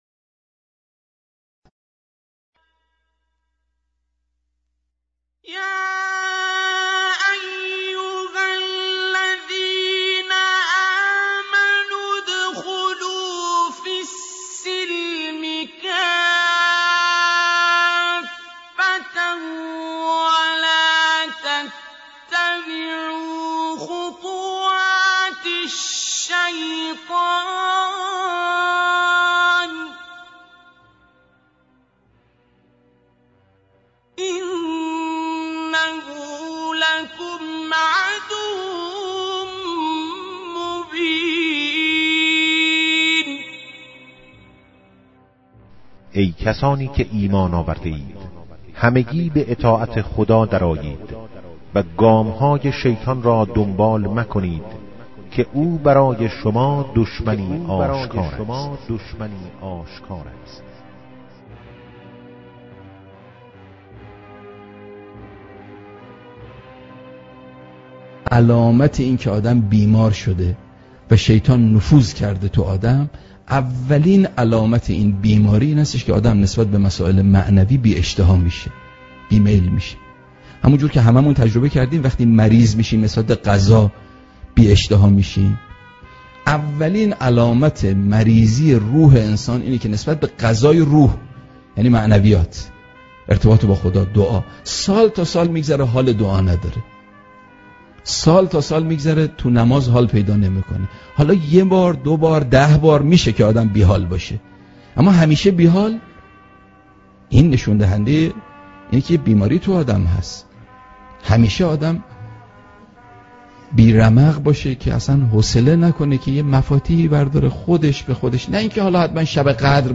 موضوع سخنرانی : علائم نفوذ شیطان
منبر مکتوب و آنلاین